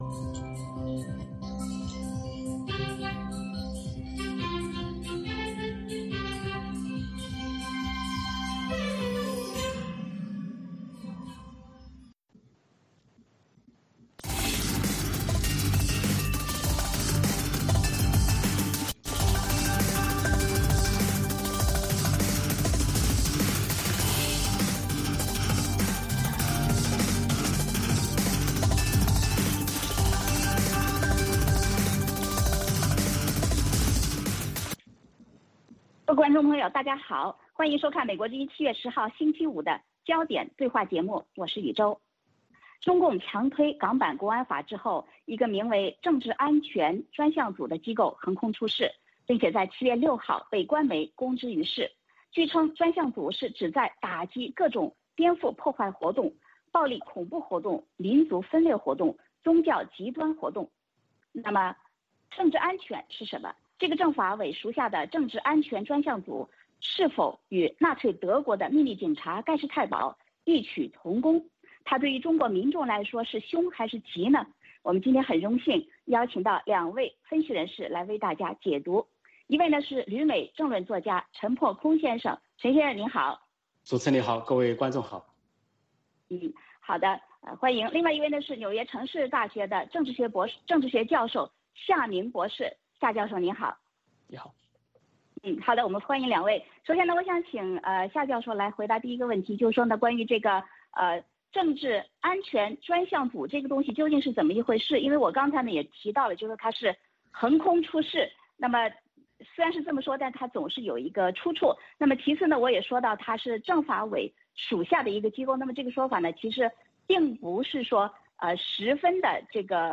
《焦点对话》节目追踪国际大事、聚焦时事热点。邀请多位嘉宾对新闻事件进行分析、解读和评论。